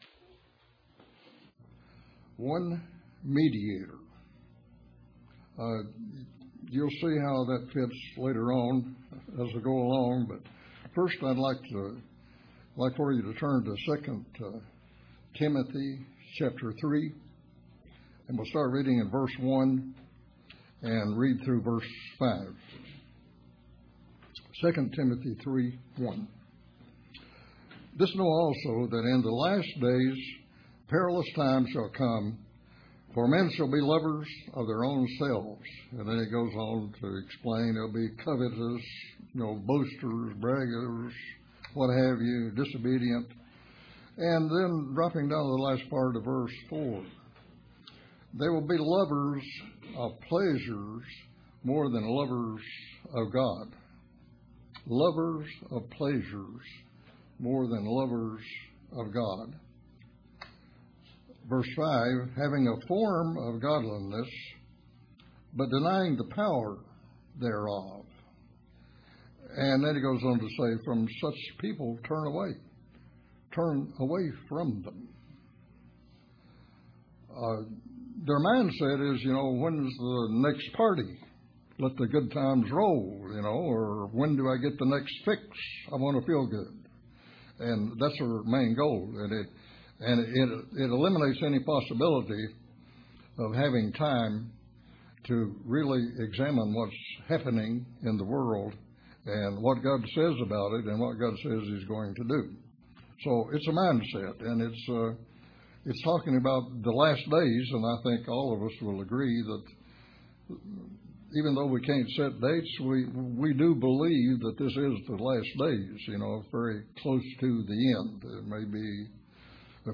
This sermon brings us to false prophets, the fear of God, who are the deceivers, and the only person who is the true Mediator.